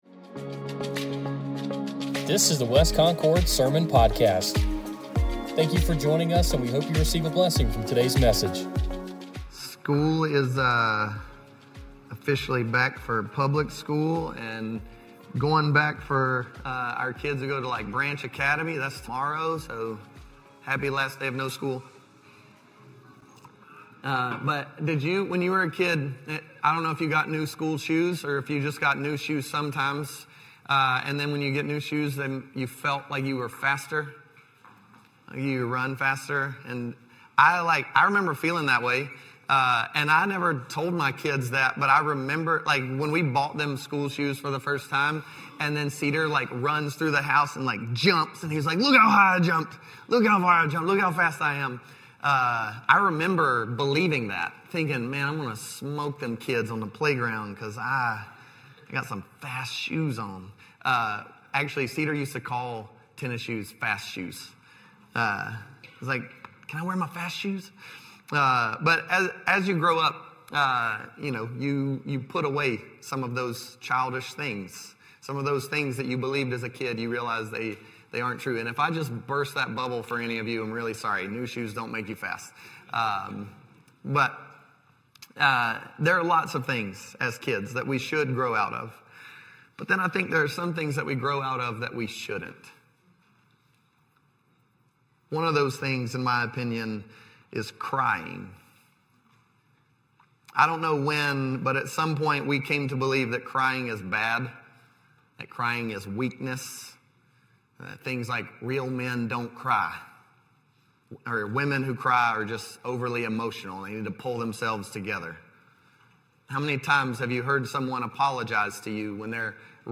Sermon Podcast | West Concord Baptist Church
Download Download A Sermon Series in the Psalms Current Sermon It's ok to cry, because God cares and he will comfort you.